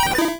Cri de Têtarte dans Pokémon Rouge et Bleu.